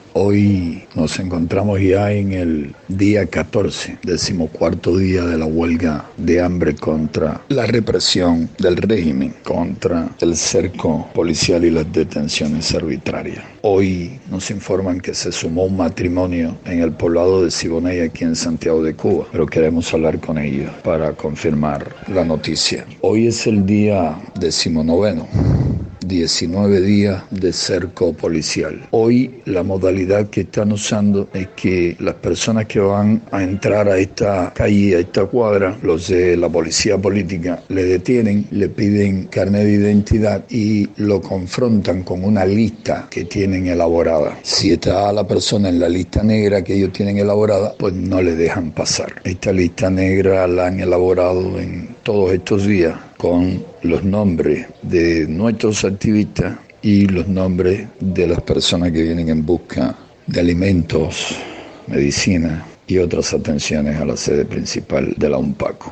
Declaraciones de José Daniel Ferrer a Radio Martí